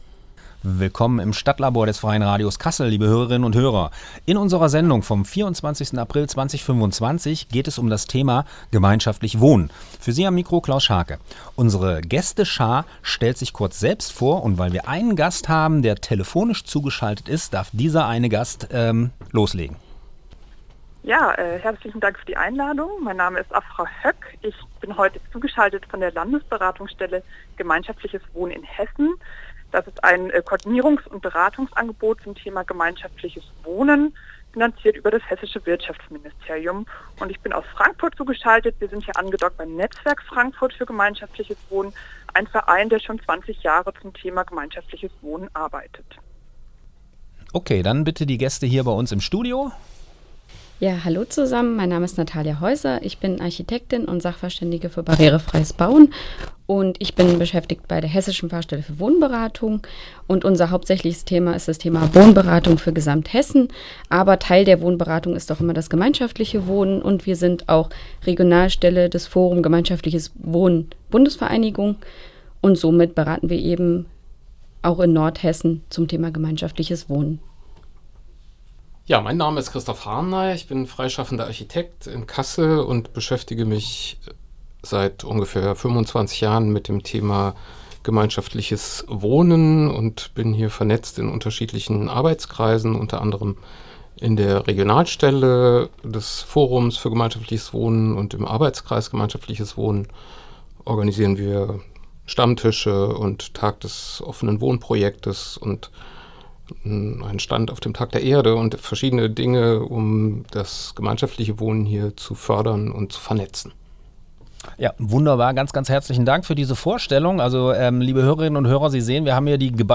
Die Musiktitel sind im Podcast nicht enthalten.